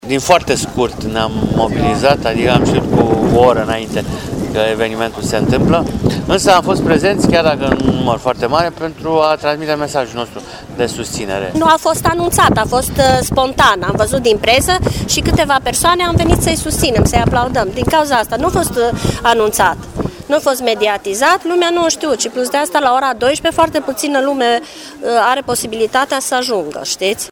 Protest al procurorilor timișoreni în fața Palatului Dicasterial
voxuri-protestatari-procurori.mp3